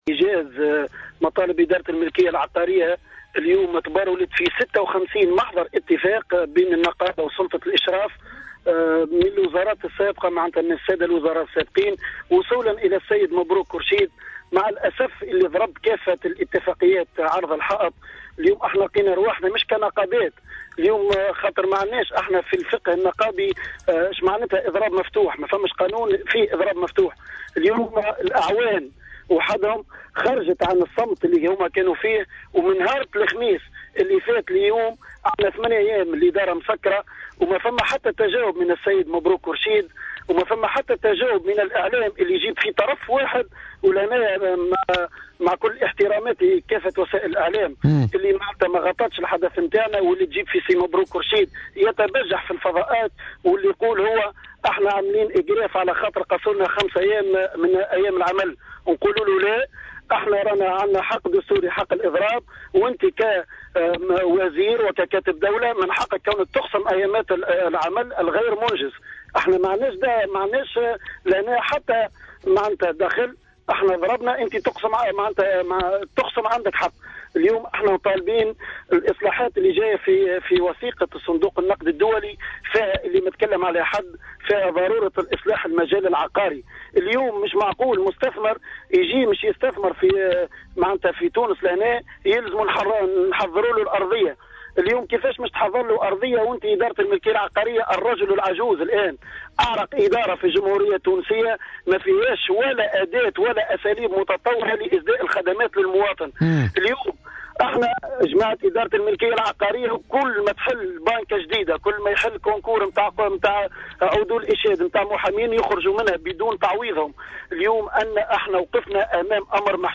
وأضاف في مداخلة له اليوم في برنامج "بوليتيكا" أن التحركات الاحتجاجية في الإدارات الجهوية تأتي على خلفية تجاهل سلطة الإشراف امحاضر الاتفاق السابقة ، مشيرا إلى أنهم لم يجدوا أي تجاوب من قبل كاتب الدّولة لدى وزيرة المالية المكلف بأملاك الدّولة والشؤون العقارية، مبروك كورشيد.